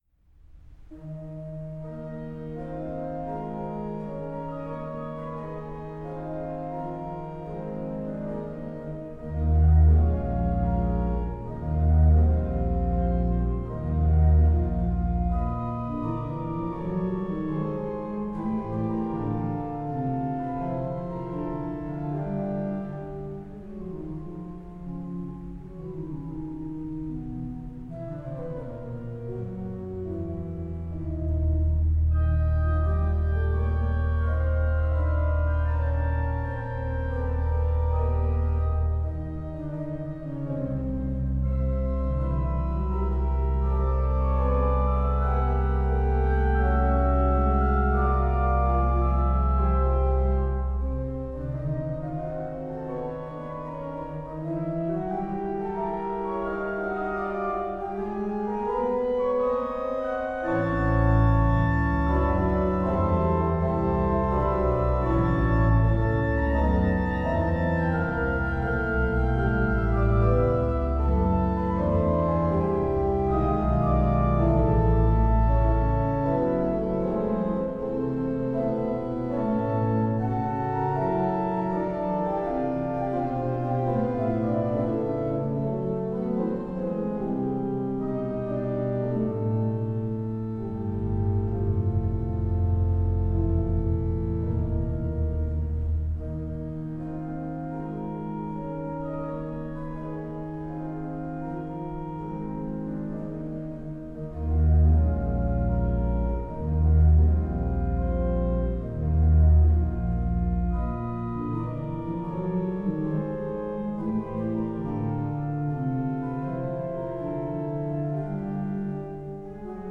Location: Ev.-luth. Ansgar-Kirche
Orgel